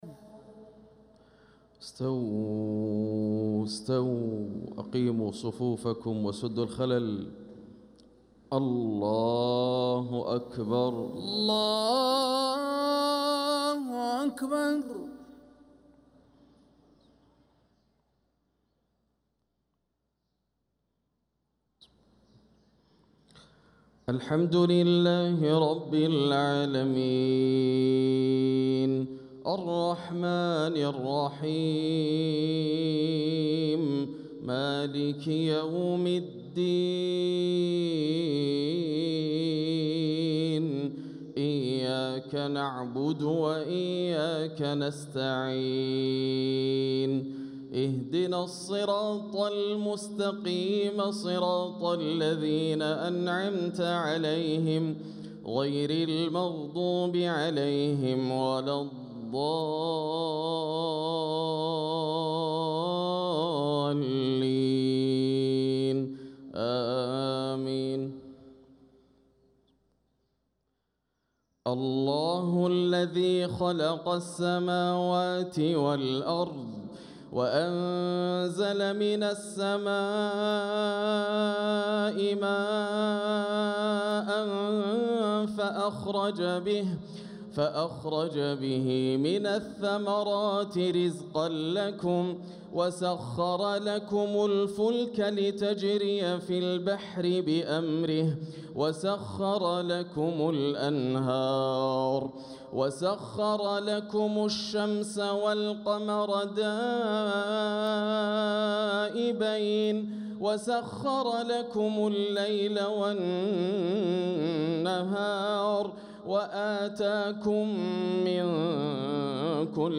صلاة المغرب للقارئ ياسر الدوسري 20 ربيع الأول 1446 هـ
تِلَاوَات الْحَرَمَيْن .